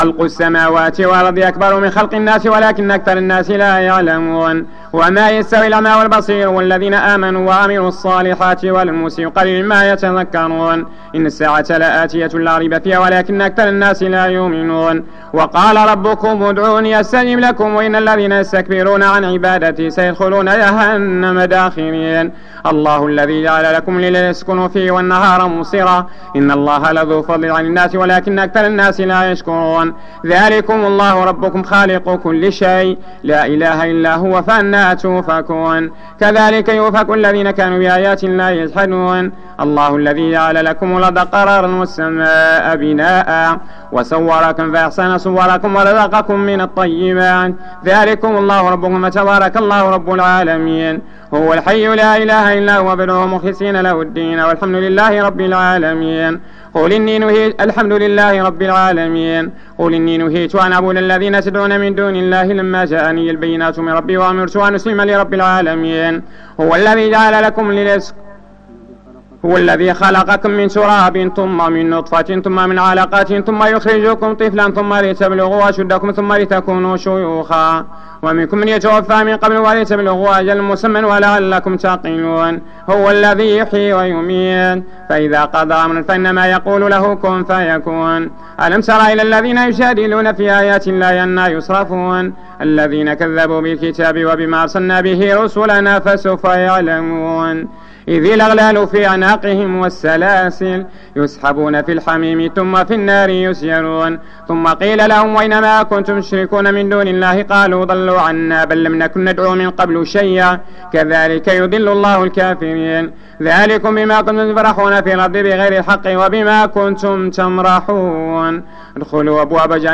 صلاة التراويح رمضان 1431/2010 بمسجد ابي بكر الصديق ف الزوى